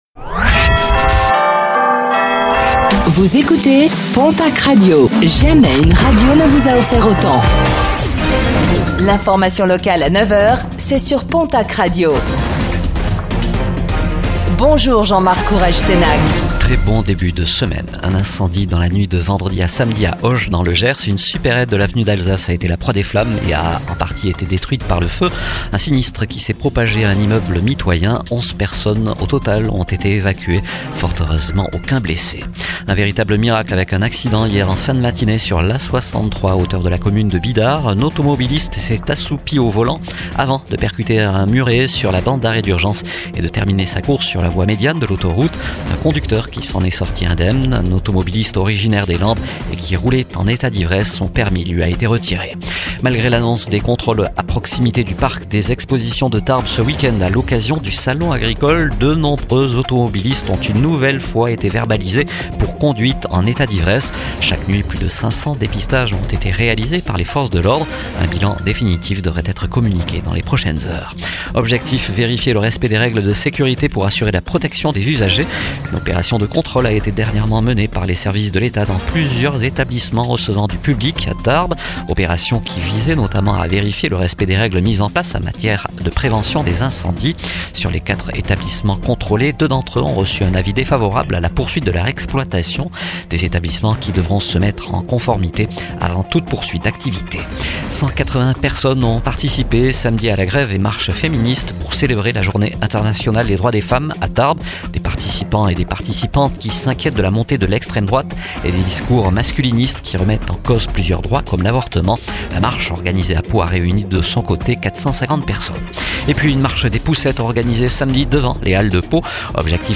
09:05 Écouter le podcast Télécharger le podcast Réécoutez le flash d'information locale de ce lundi 09 mars 2026